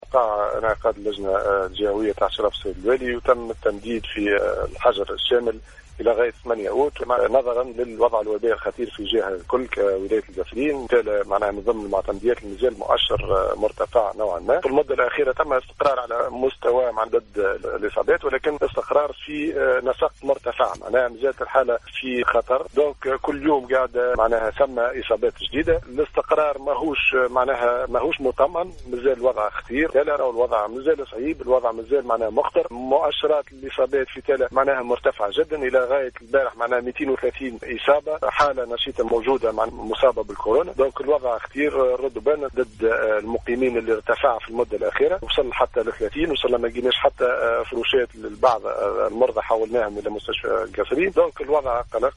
أفاد معتمد تالة جوهر الشّعباني أثناء تدخّله صباح اليوم السّبت 21 جويلية 2021 ببرنامج على كلّ لون بإذاعة السيليوم أ ف أ م ، أنّ الوضع الوبائي بالمعتمدية خطير و صعب جدّا،  حيث تشهد استقرارًا غير مطمئن في عدد الإصابات في الآونة الأخيرة .